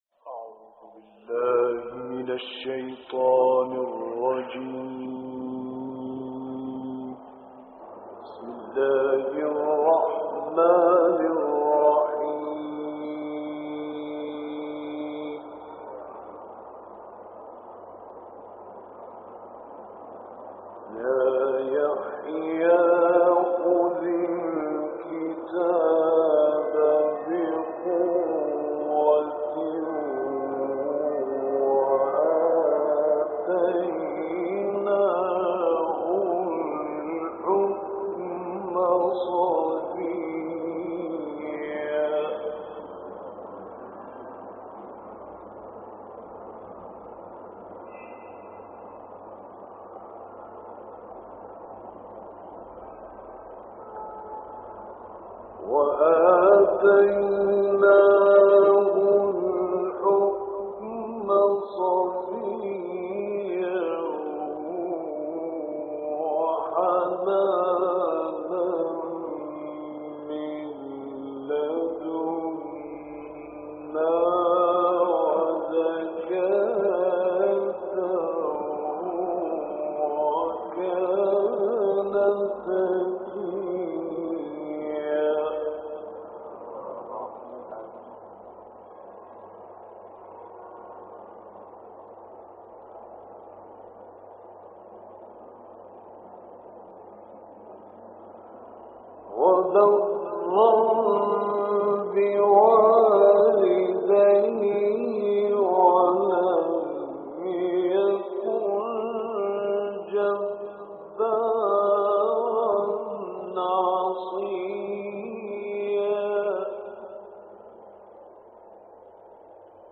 دانلود قرائت سوره مریم آیات 12 تا 35 - استاد راغب مصطفی غلوش